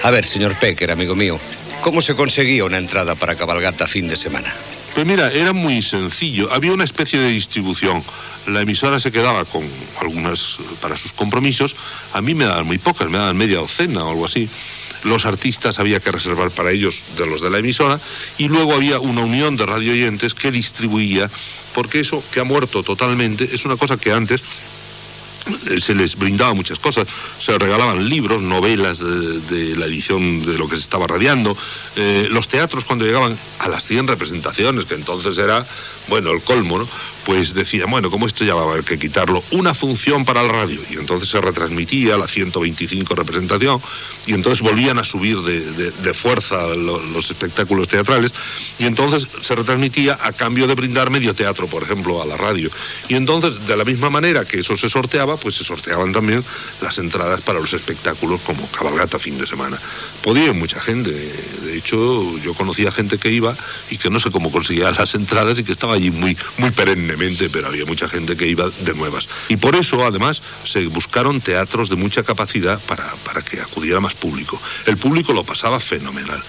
El presentador José Luis Pécker parla del programa "Cabalgata fin de semana" a la Cadena SER
Divulgació